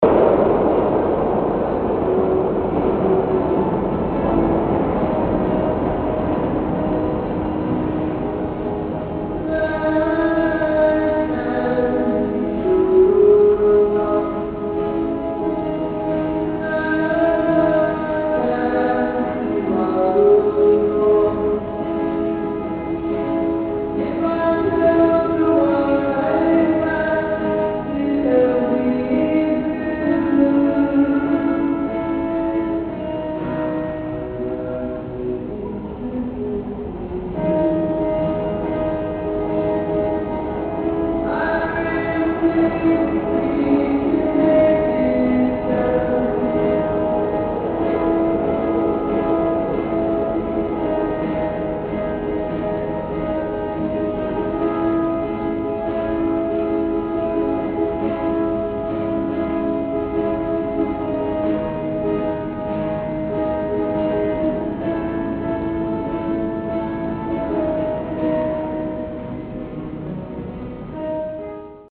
Here is a clip of a guy singing
Pretty cool sound.